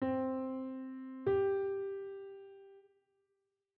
Perfect 5th
C-Perfect-Fifth-Interval-S1.wav